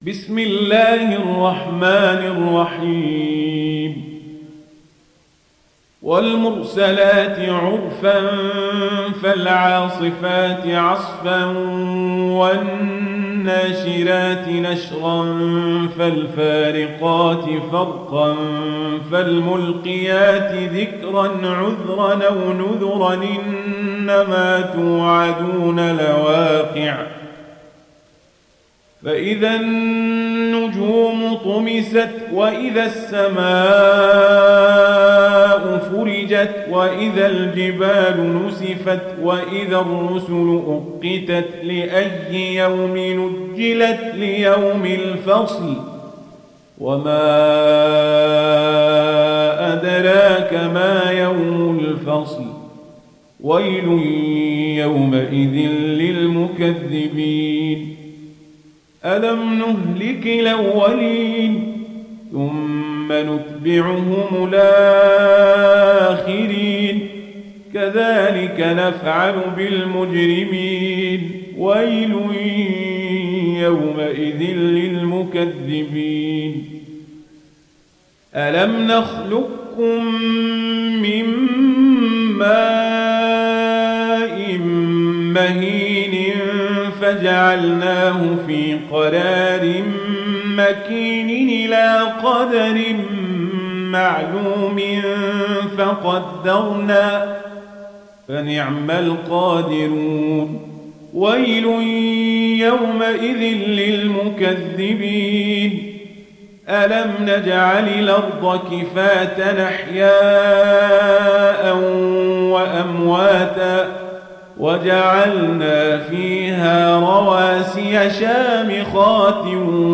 دانلود سوره المرسلات mp3 عمر القزابري روایت ورش از نافع, قرآن را دانلود کنید و گوش کن mp3 ، لینک مستقیم کامل